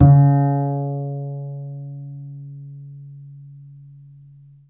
DBL BASS D.1.wav